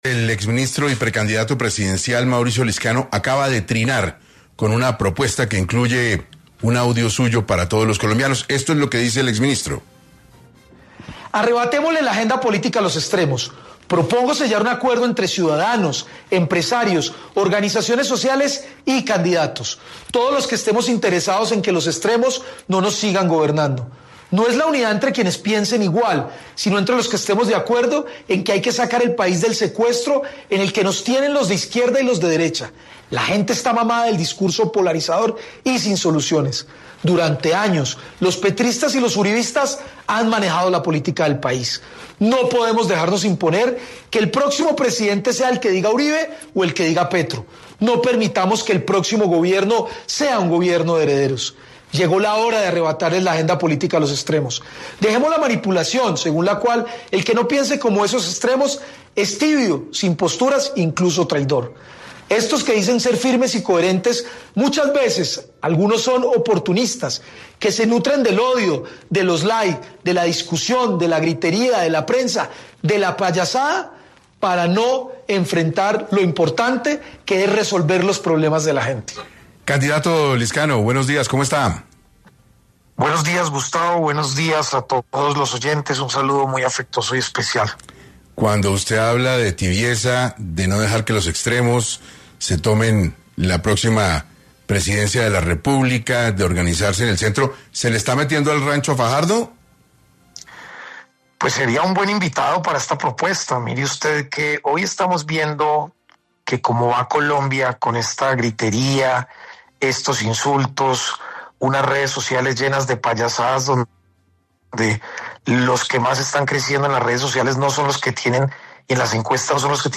Lizcano, aseguró en entrevista con 6AM de Caracol Radio, que se está viendo en Colombia un fenómeno en las redes en el que las personas que están haciendo más ruido son los que están llevando al país a tener que elegir en las próximas elecciones de 2026 por la extrema derecha o la extrema izquierda.